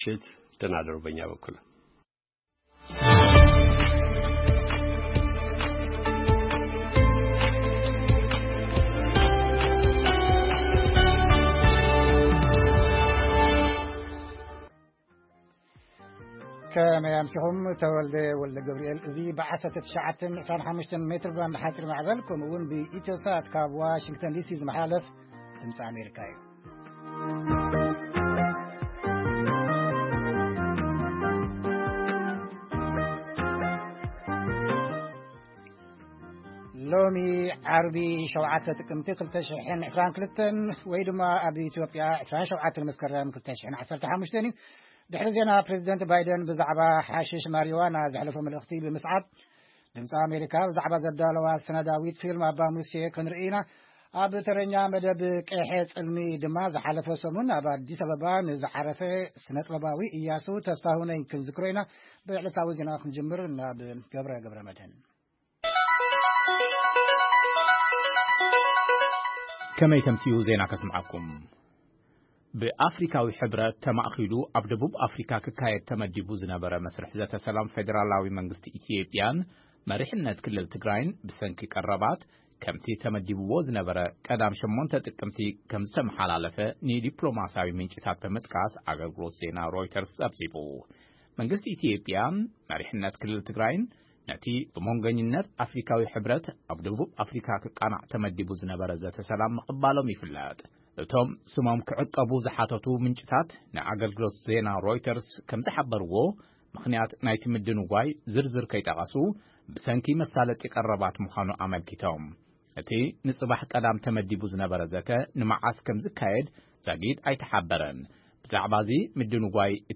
ፈነወ ድምጺ ኣመሪካ ቋንቋ ትግርኛ ጥቅምቲ 7,2022 ዜና (ምድንጓይ ዘተ ሰላም ኢትዮጵያ: ሕብረት ኣውሮጳ ንመጥቓዕቲ ህጻናት ክልል ትግራይ ዝምልከት ዘሕለፎ ውሳነን ካልኦትን) መደብ ቂሔ ጽልሚ የጠቓልል